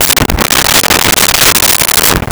Glass On Bar With Slide 02
Glass On Bar With Slide 02.wav